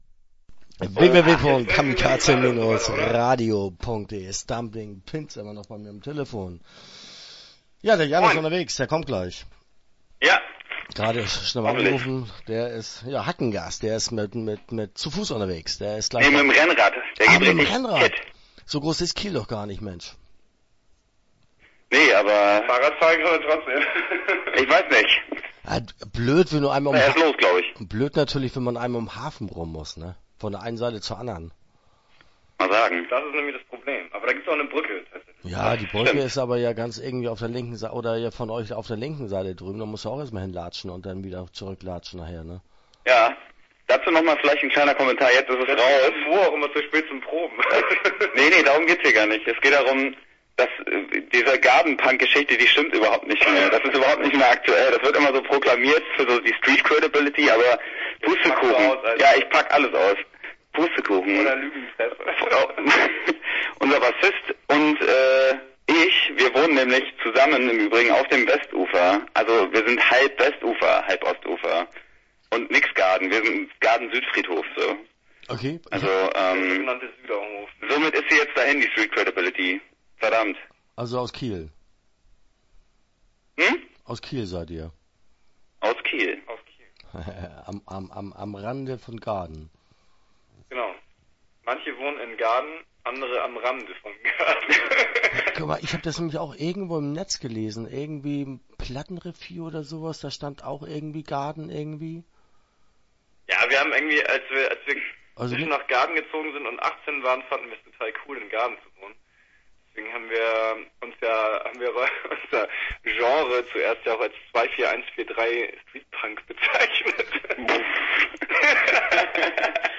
Stumbling Pins - Interview Teil 1 (12:58)